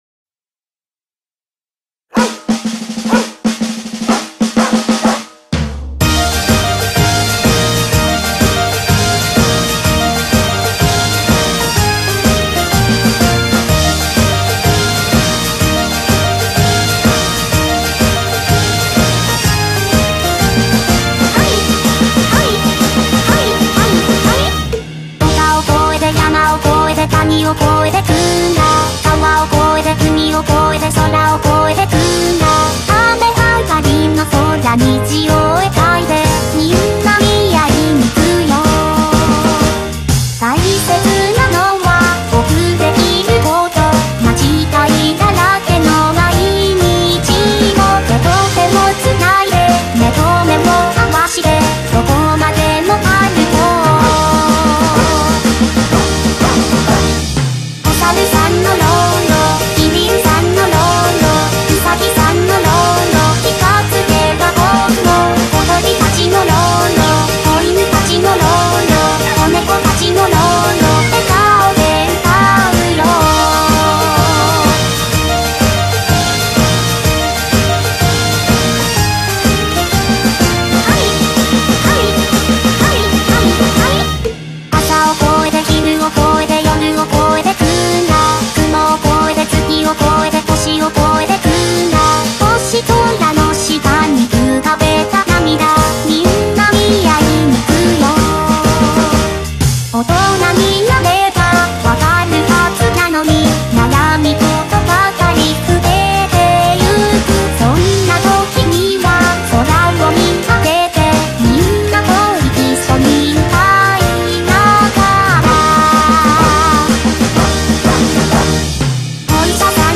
BPM125
Audio QualityCut From Video